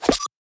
poleSwing.ogg